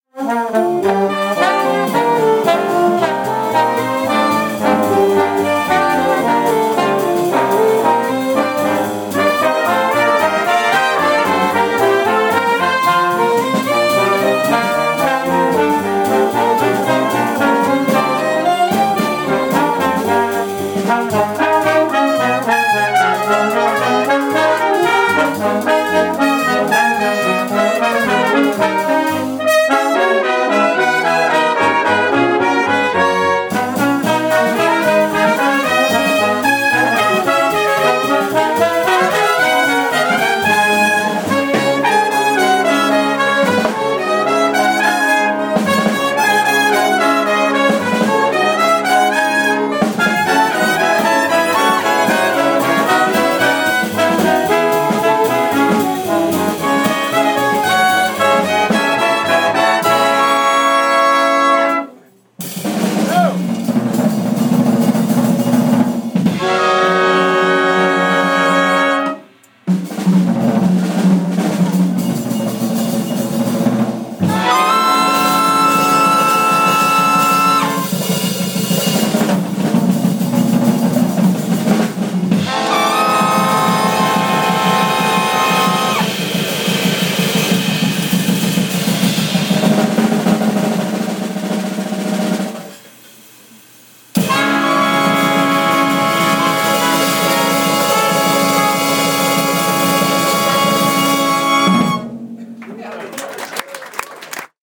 Bohemian Caverns band